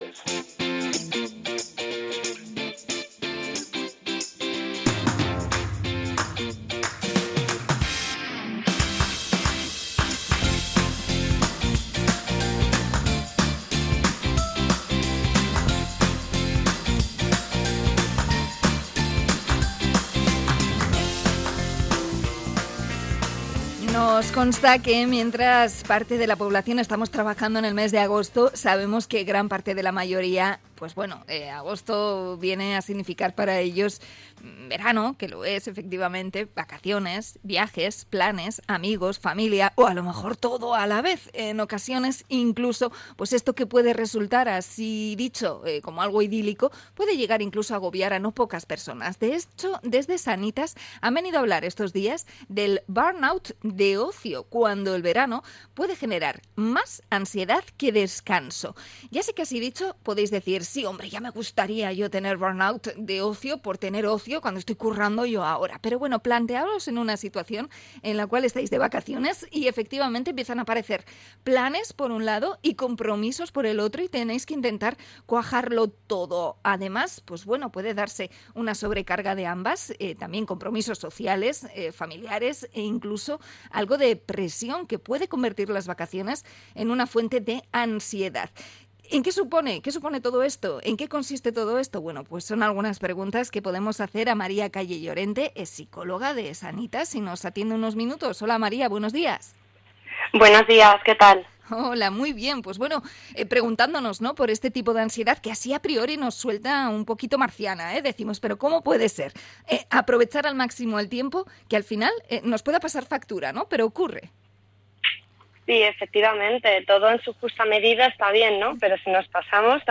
Entrevista a psicóloga sobre la ansiedad de las vacaciones